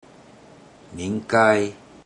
Click each Romanised Teochew word or phrase to listen to how the Teochew word or phrase is pronounced.
nin42kai1 (emphasis nin4kai10)